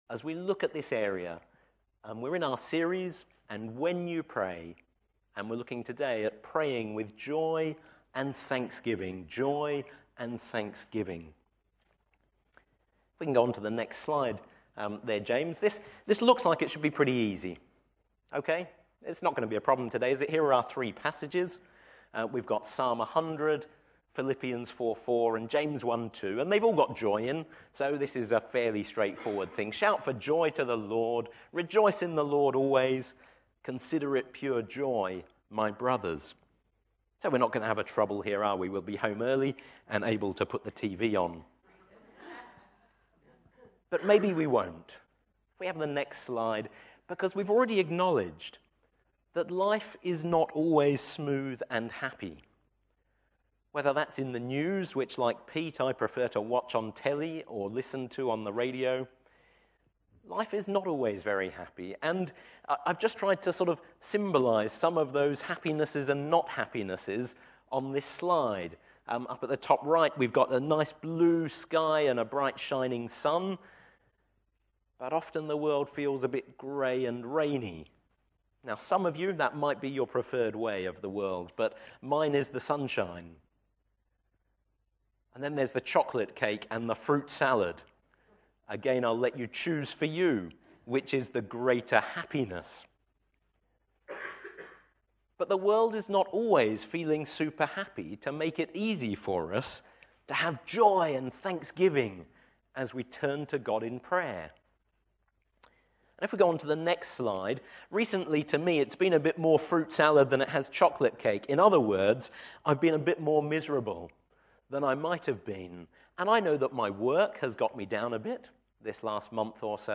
NOTE: We apologise, but due to a technical problem this recording does not include the last 5 minutes.